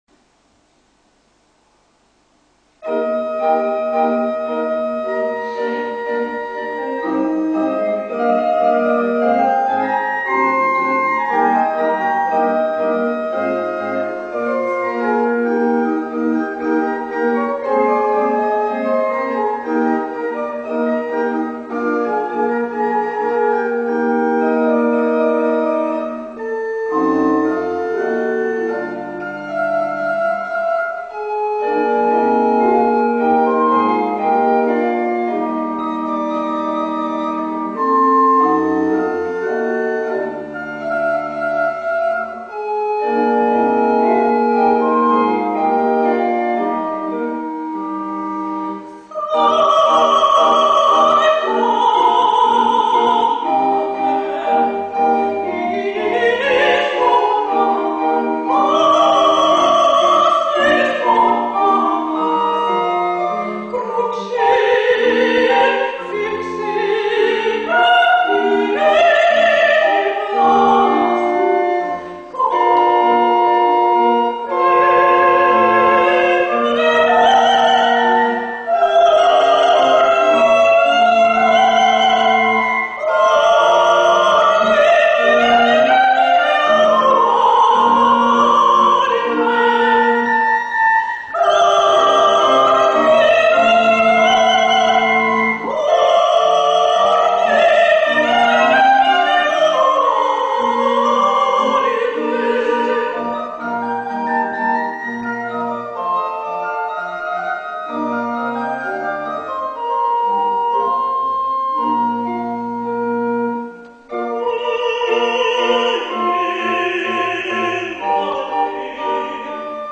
"Stabat Mater" Duette (Pergolesi)